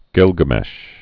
(gĭlgə-mĕsh)